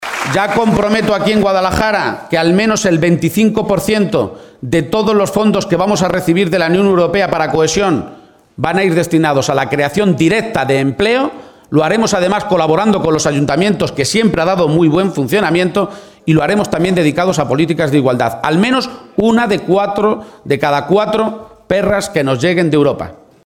Asume cuatro compromisos firmes en su primer acto de campaña electoral, celebrado en Guadalajara